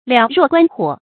燎若觀火 注音： ㄌㄧㄠˊ ㄖㄨㄛˋ ㄍㄨㄢ ㄏㄨㄛˇ 讀音讀法： 意思解釋： 謂事理清楚明白，如看火一般。